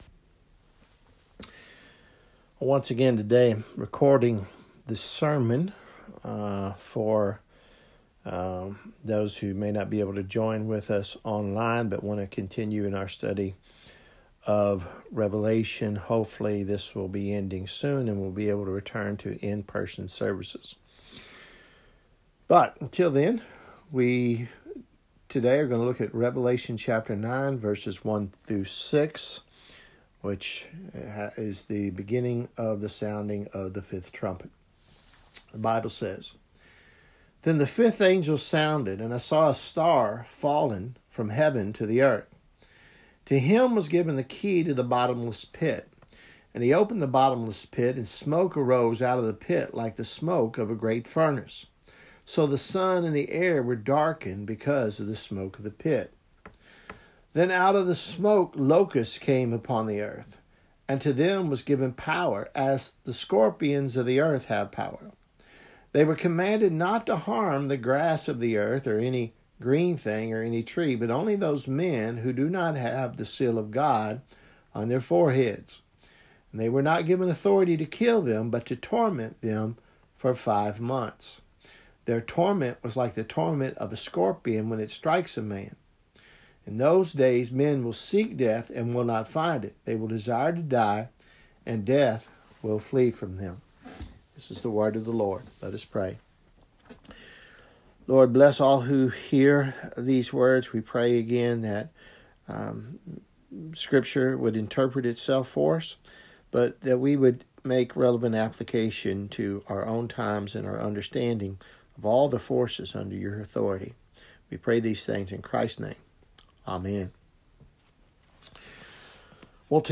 Revelation sermon series , Sermons May 10 2020 “All Hell Breaks Loose